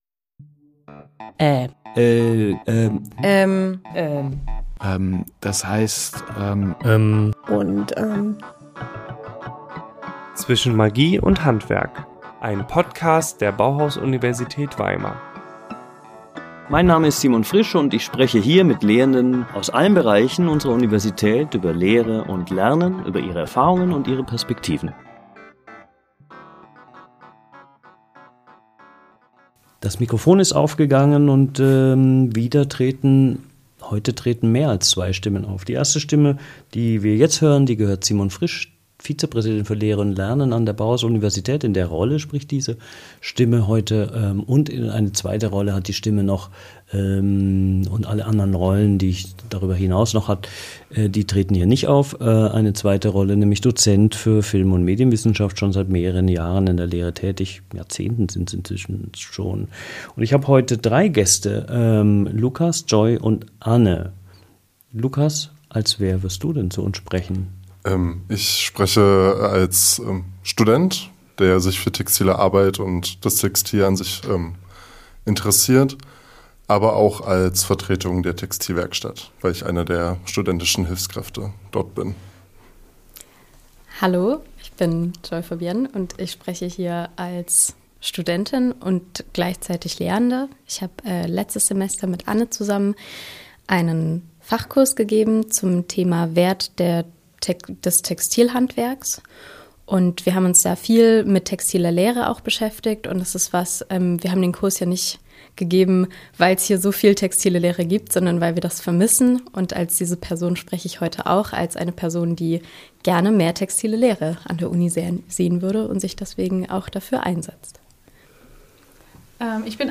Das Gespräch beleuchtet die Faszination am Textil.